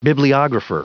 Prononciation du mot bibliographer en anglais (fichier audio)
Prononciation du mot : bibliographer